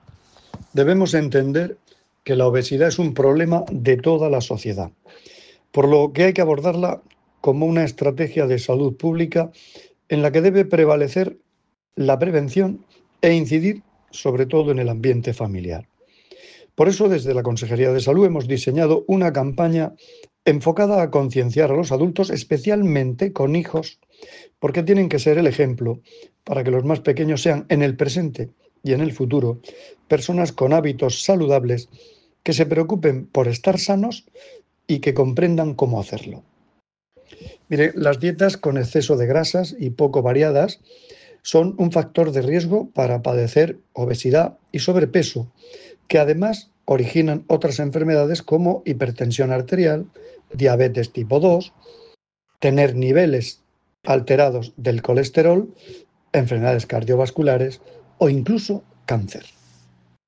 Sonido/ Declaraciones del consejero de Salud, Juan José Pedreño [mp3], sobre la campaña 'Ídolos', que promueve la alimentación saludable y la prevención de la obesidad.